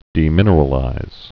(dē-mĭnər-ə-lĭz)